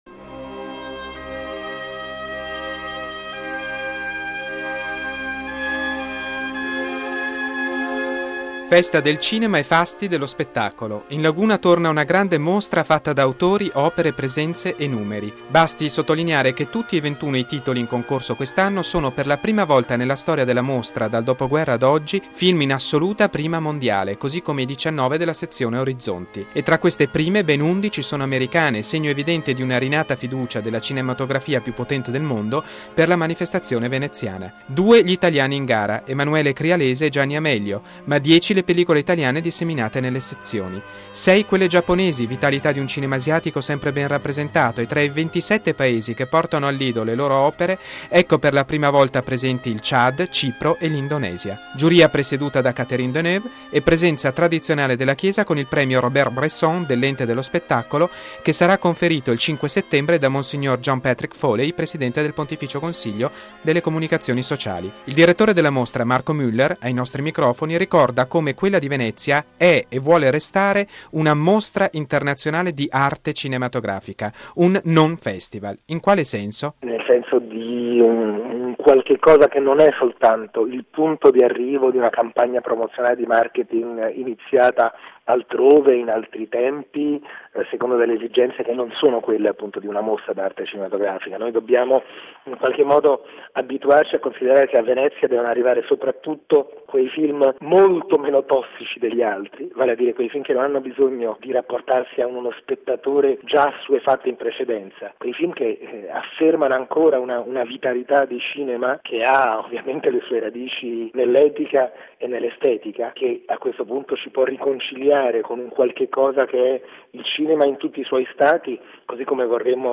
Un’edizione ricca di titoli e autori, che coniuga cultura e spettacolo, punto di riferimento per la cinematografia di tutto il mondo. Da Venezia il servizio del nostro inviato